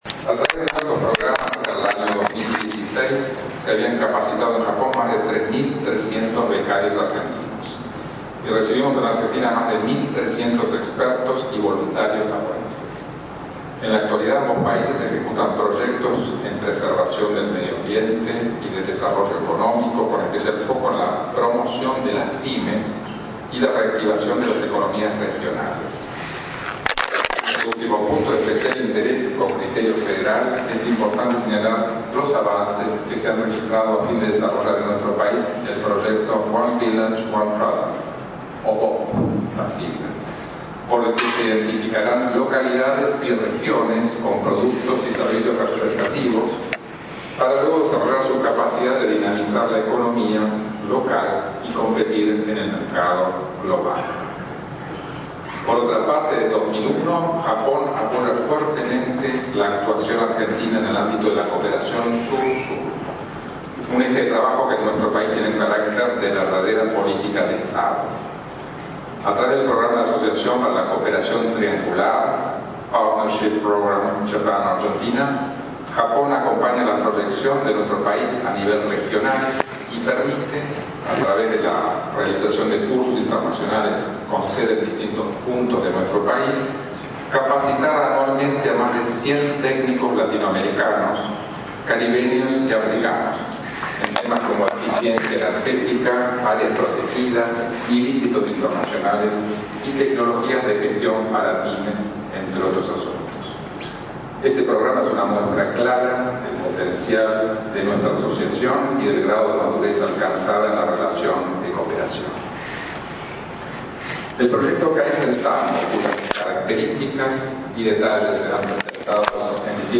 En las bellísimas  instalaciones del Palacio San Martín conservadas y cuidadas como en sus orígenes  el miércoles 25  de octubre se lanzó oficialmente el proyecto “Red de Asistencia Técnica para Oportunidades Globales de Kaizen”, denominado  “Kaizen-Tango”